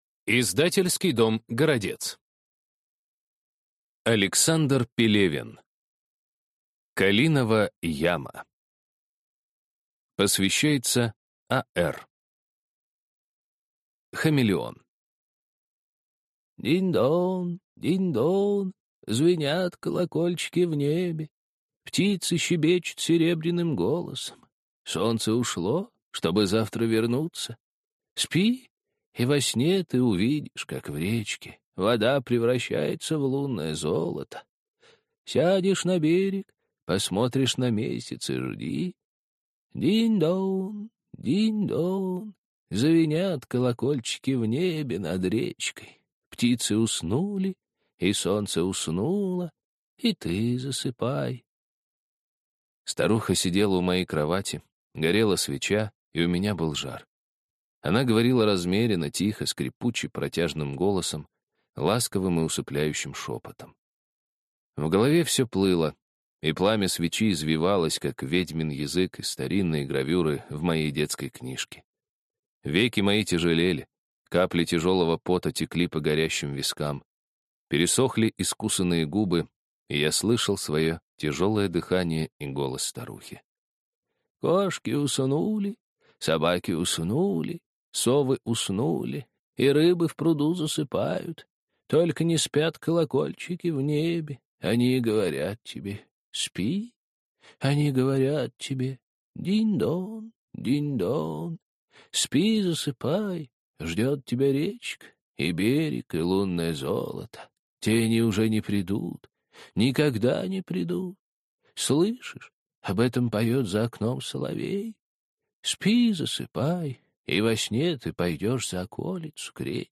Аудиокнига Калинова Яма | Библиотека аудиокниг